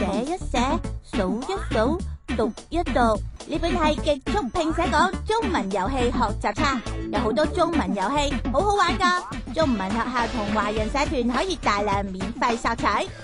My voice is warm, friendly, clear, gentle, enthusiastic, firm, and sweet, with the versatility to perform across e‑learning, narration, commercial work, character voice acting, and more.
Character / Cartoon
Kid Voice For Cartoon